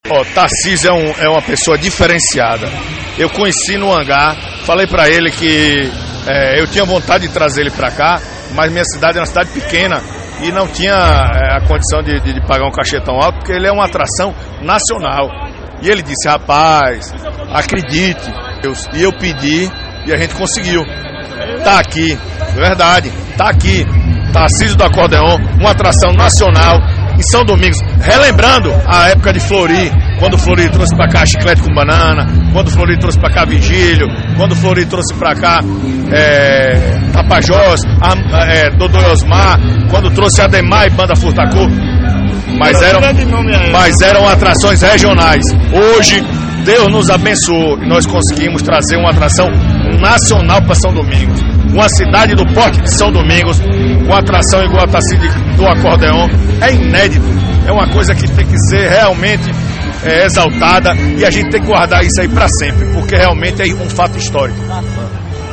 a mutidão cantava e se emocionava cada canção do forrozeiro.
forró e vaquejada